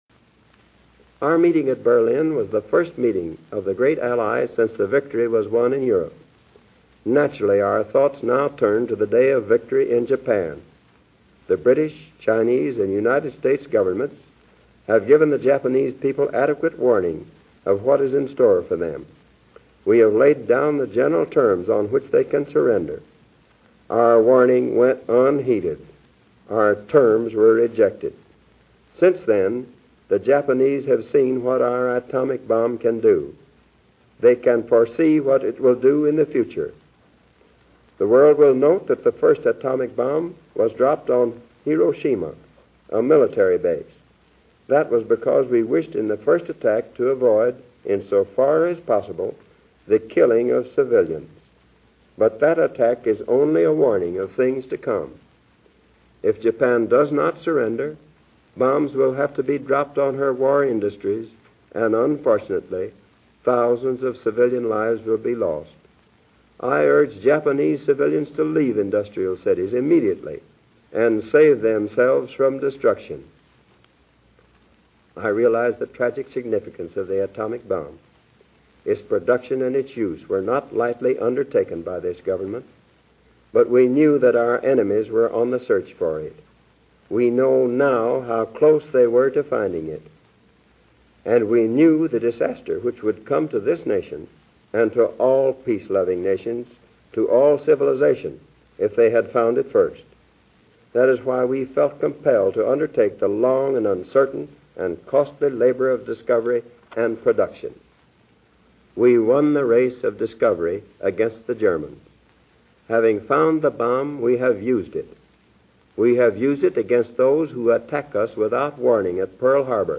Harry S. Truman's Atomic Bomb Address - August 9, 1945 | From the Collection to the Classroom
Listen as the President explains the United States' rationale for developing and utilizing the atomic bomb in Japan.